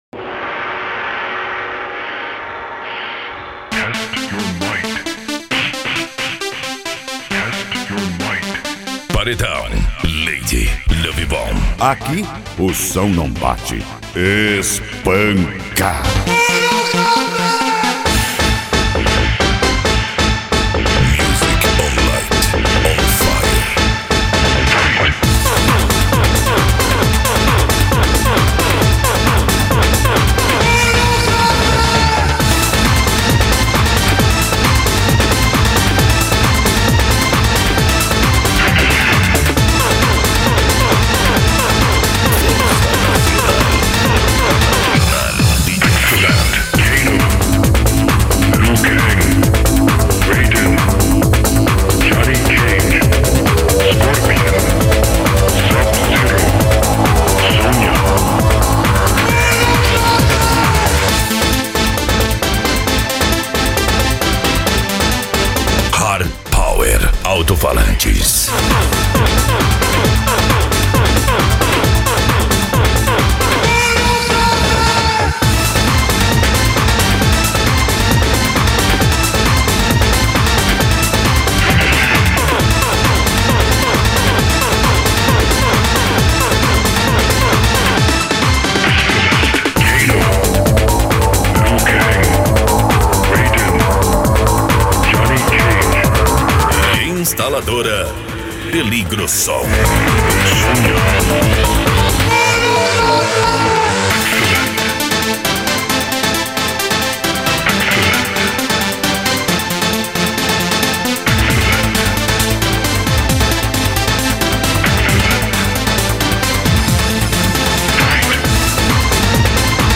Deep House
Electro House
Eletronica